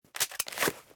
bullet_supply.ogg